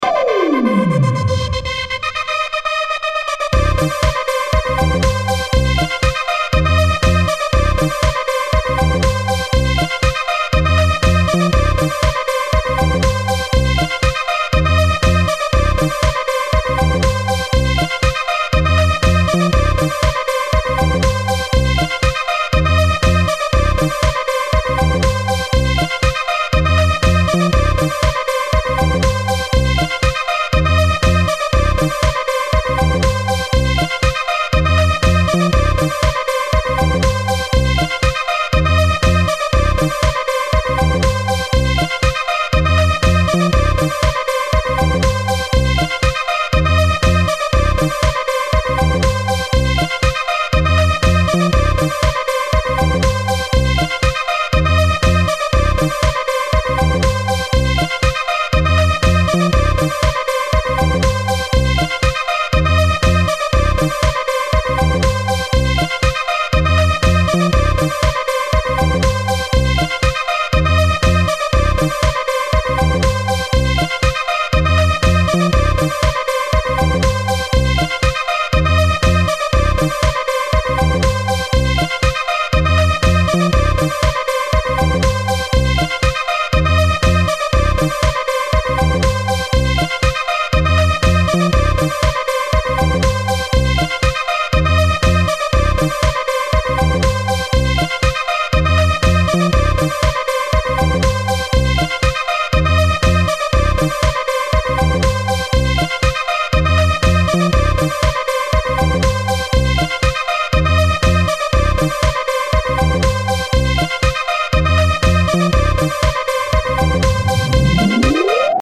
Home > Music > Beats > Electronic > Bright > Running